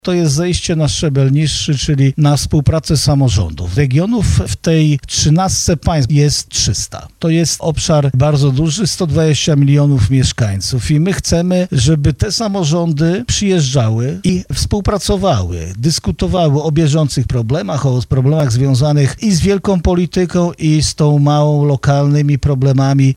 O tym czym jest  Samorządowy Kongres Trójmorza mówi Marszałek Województwa Lubelskiego, Jarosław Stawiarski.